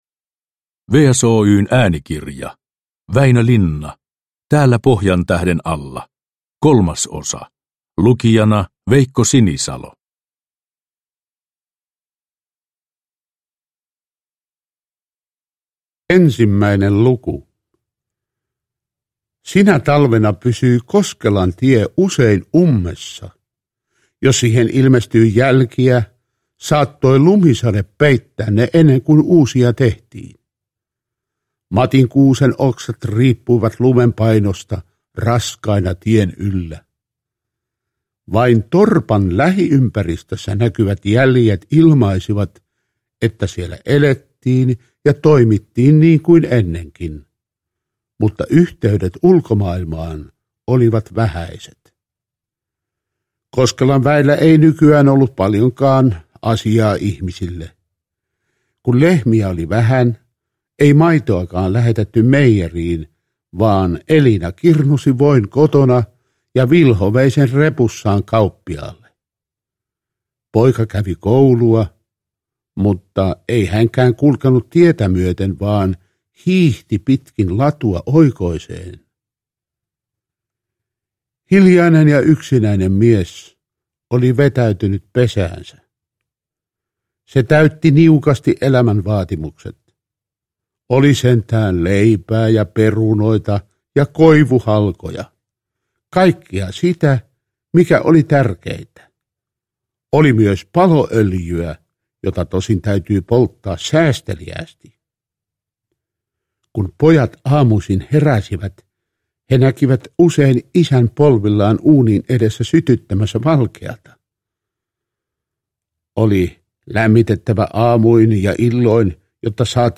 Veikko Sinisalon voimakkasti eletty esitys syventää Linnan taitavaa ihmiskuvausta. Äänikirjan kertojana on Veikko Sinisalo.
Uppläsare: Veikko Sinisalo